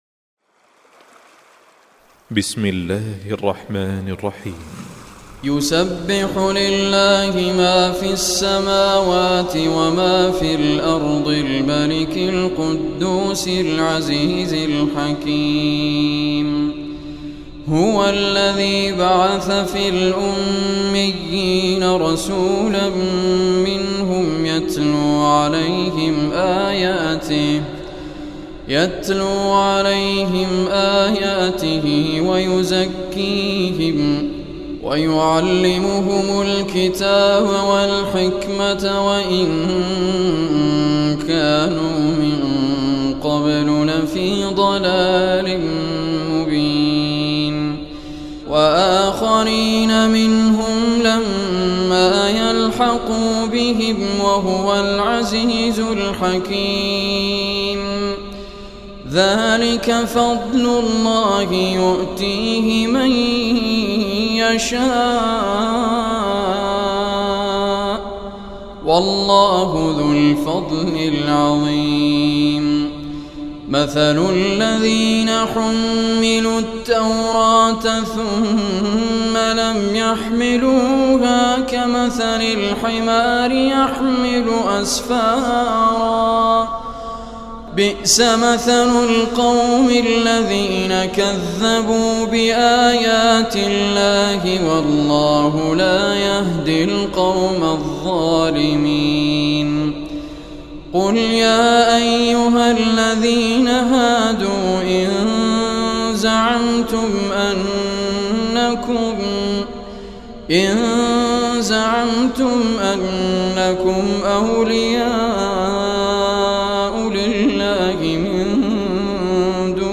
Surah Jumuah Recitation